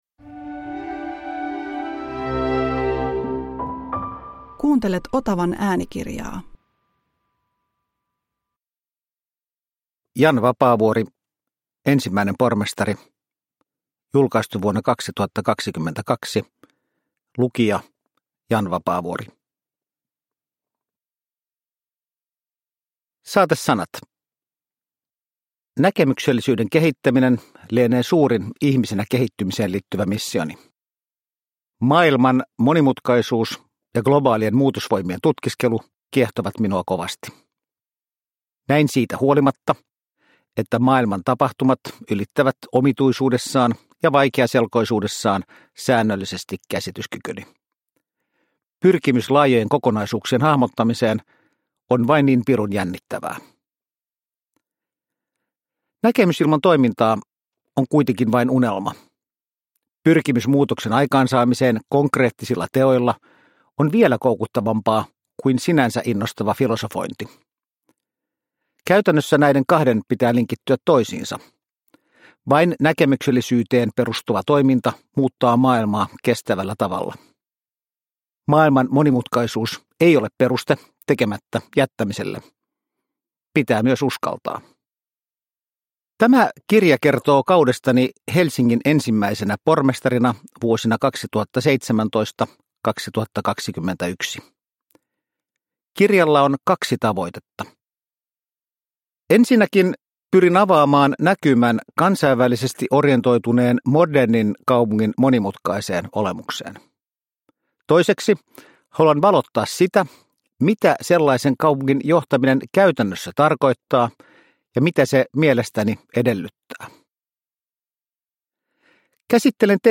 Ensimmäinen pormestari – Ljudbok – Laddas ner
Uppläsare: Jan Vapaavuori